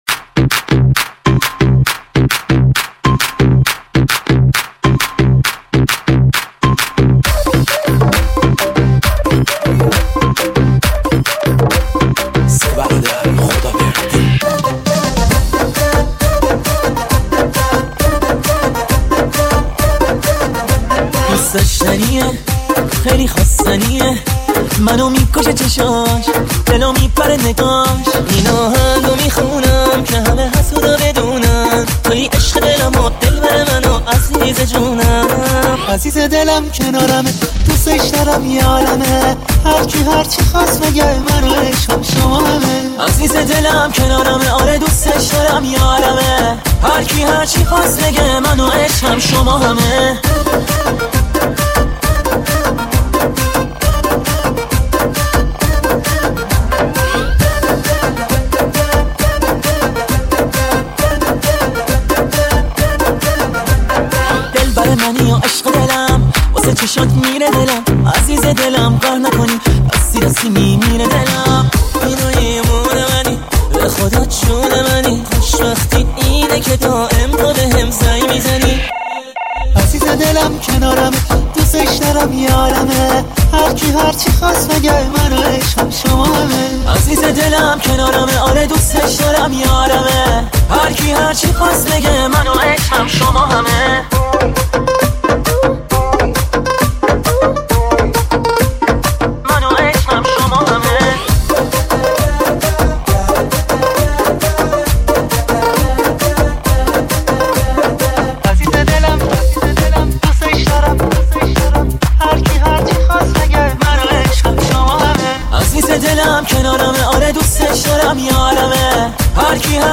دانلود ریمیکس شاد جدید
ریمیکس شاد ارکستی
ریمیکس شاد رقص و دنس
ریمیکس شاد و بندری مخصوص رقص